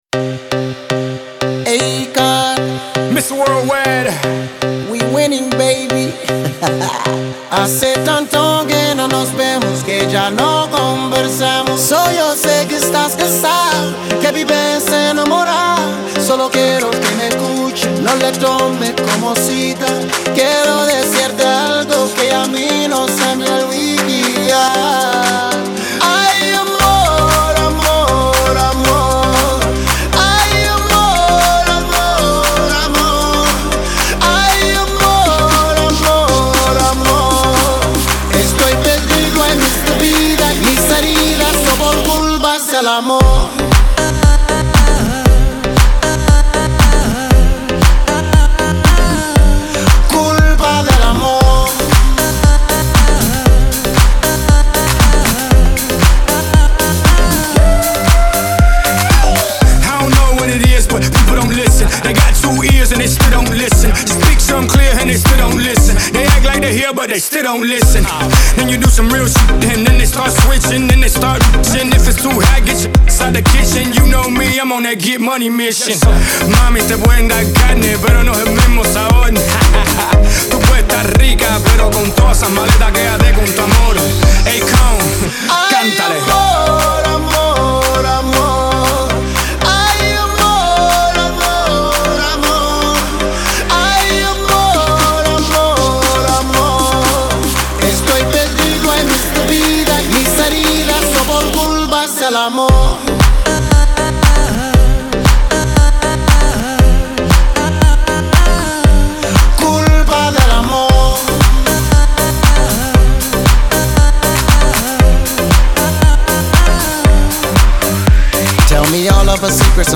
это энергичный трек в жанре латино-рэп и реггетон